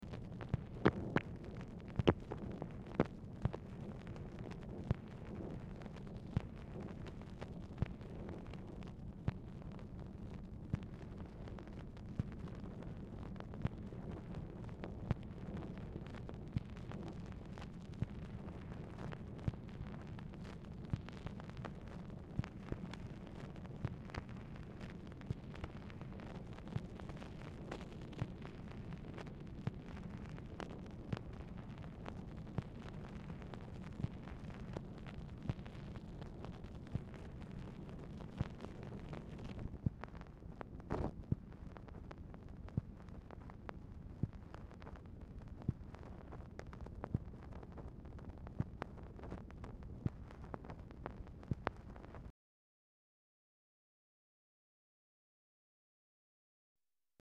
Telephone conversation # 5277, sound recording, MACHINE NOISE, 8/31/1964, time unknown | Discover LBJ
Telephone conversation
Dictation belt
LBJ Ranch, near Stonewall, Texas